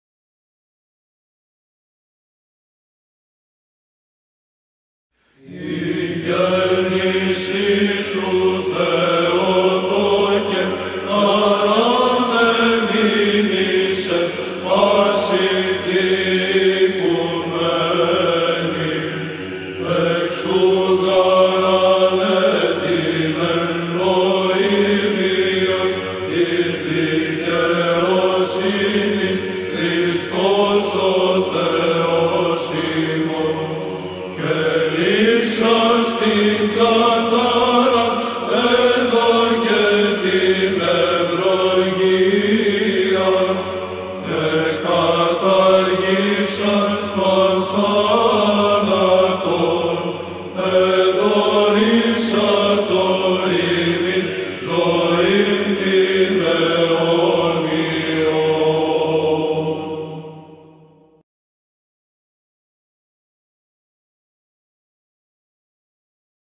Ἀπολυτίκιον. Ἦχος δ’.